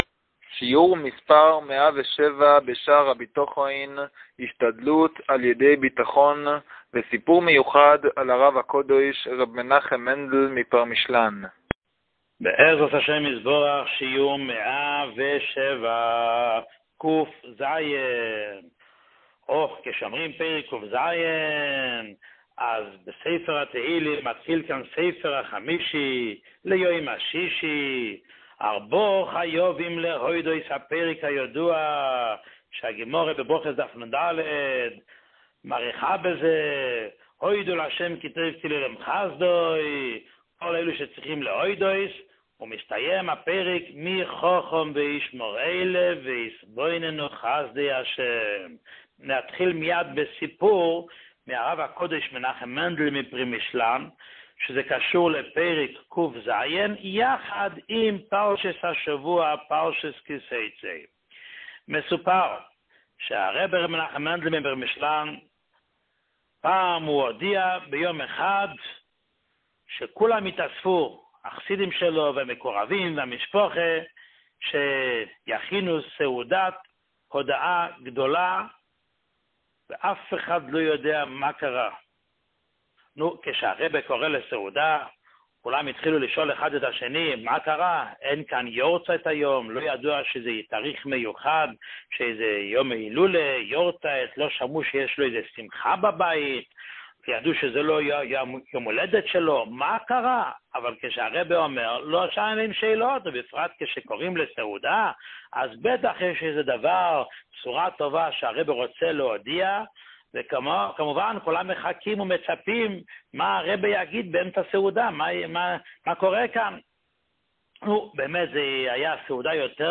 שיעור 107